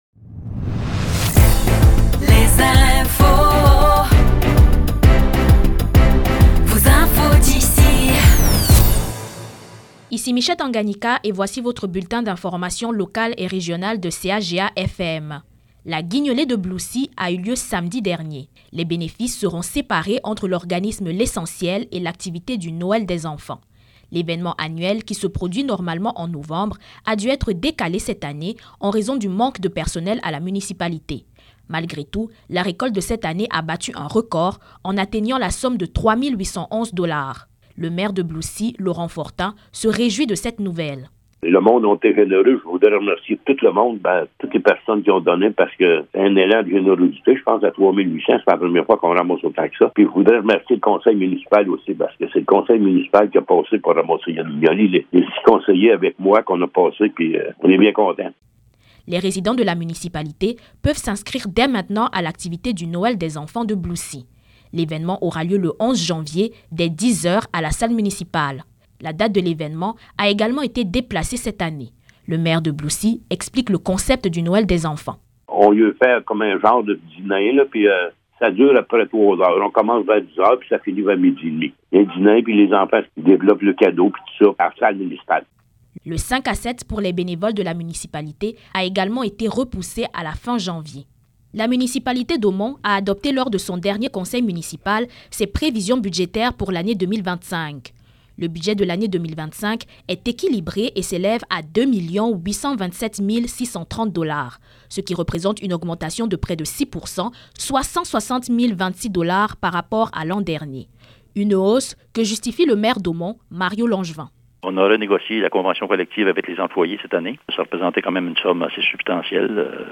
Nouvelles locales - 19 décembre 2024 - 12 h